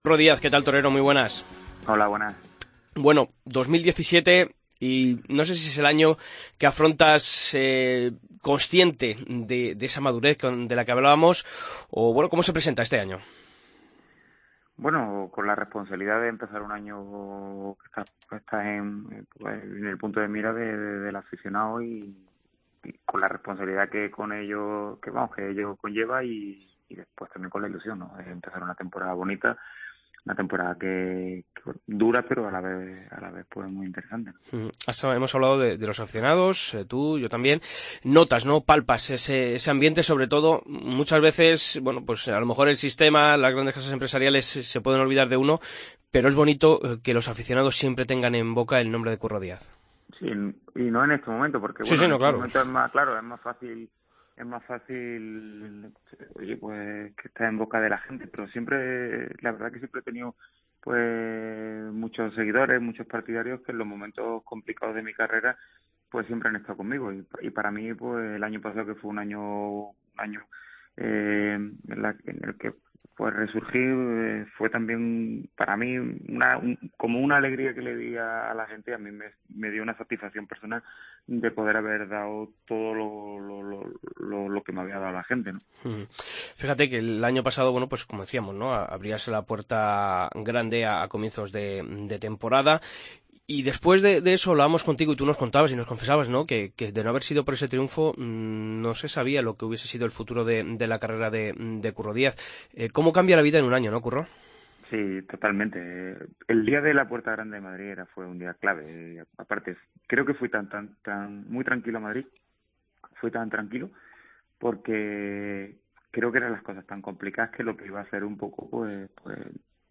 Escucha la entrevista a Curro Díaz en El Albero